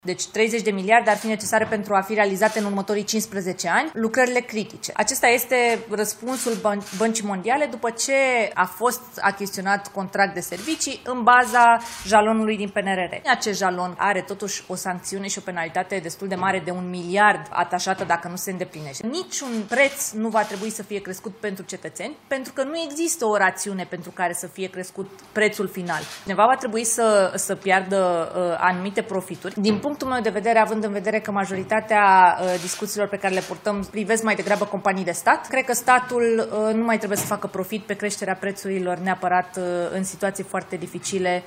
Ministra Mediului, Diana Buzoianu: „30 de miliarde ar fi necesare pentru realizarea, în următorii 15 ani, lucrărilor critice”